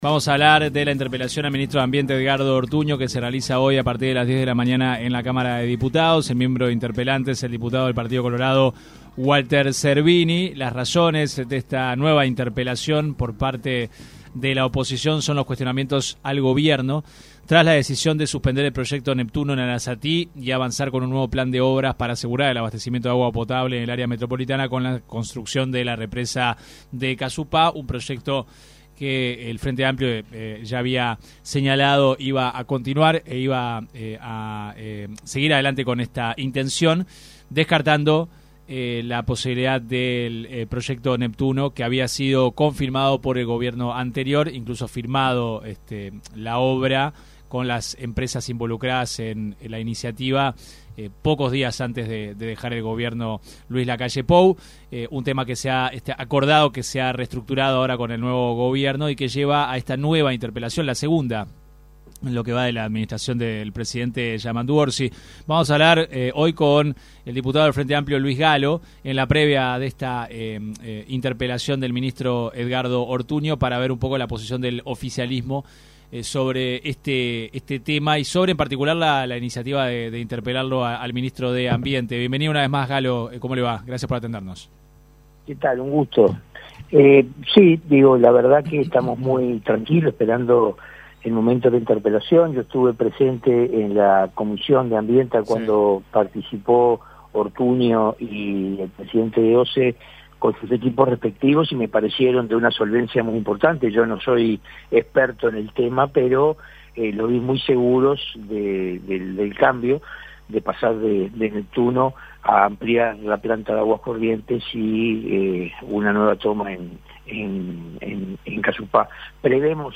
El diputado del Frente Amplio, Luis Gallo en entrevista con 970 Noticias expresó su opinión si sería necesario condicionar la utilización de la eutanasia a que anteriormente se realicen cuidados paliativos al paciente.